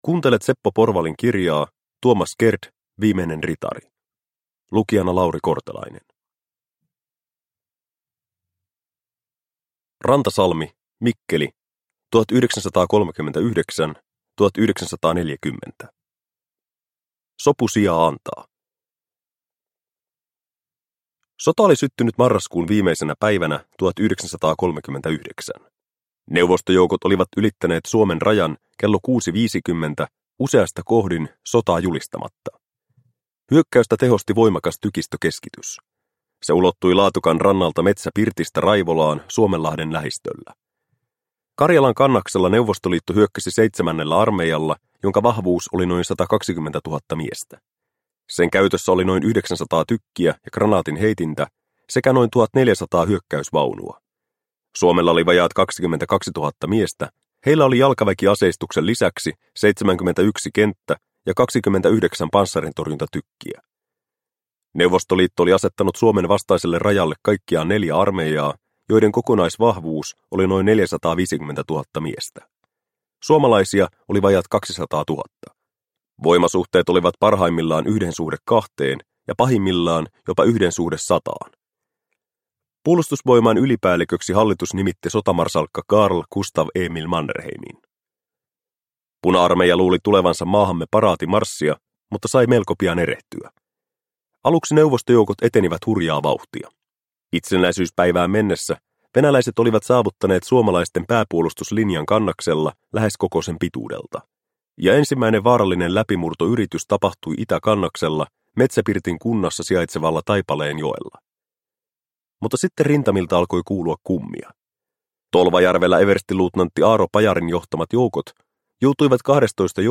Tuomas Gerdt – Ljudbok – Laddas ner